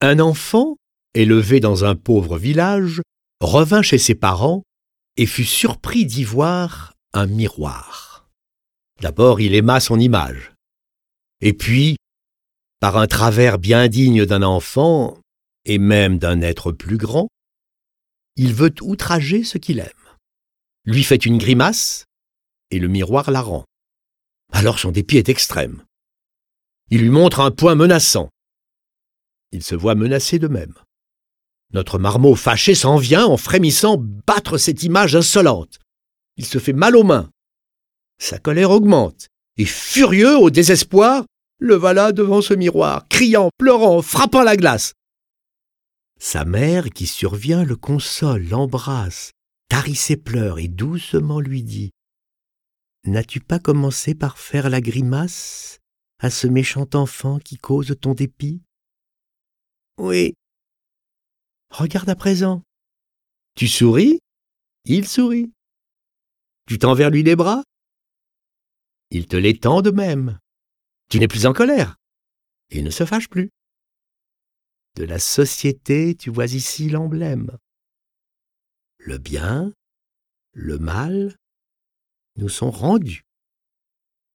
Toute sa vie, Florian aura rédigé des textes optimistes et en même temps plus futés qu'il n'y paraît. Interprétées par 7 voix et accompagnées de plusieurs morceaux de musique classique, ces 18 fables complétées de 5 commentaires enchanteront nos oreilles. Les poèmes sont illustrés avec les musiques de Bach, Beethoven, Brahms, Chopin, Debussy, Fauré, Grieg, J. Strauss et Vivaldi.